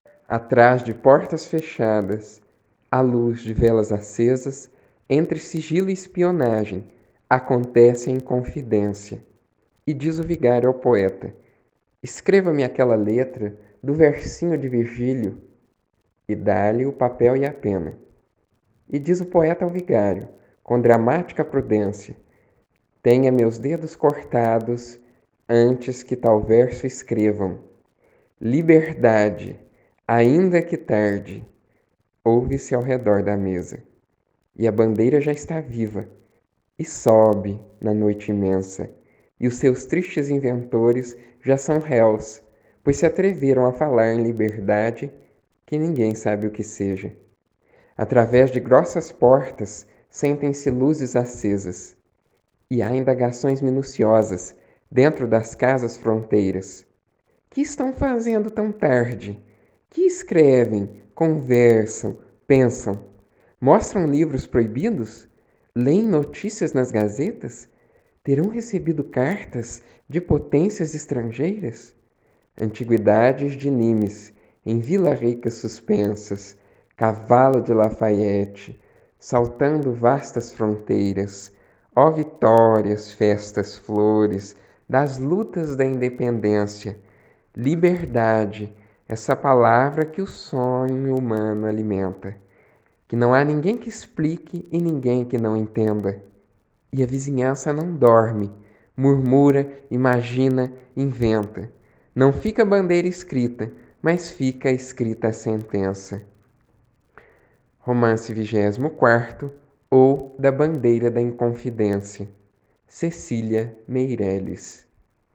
Mapa-05-Lutas-por-Liberdade-Trecho-do-Romanceiro-da-Independencia.ogg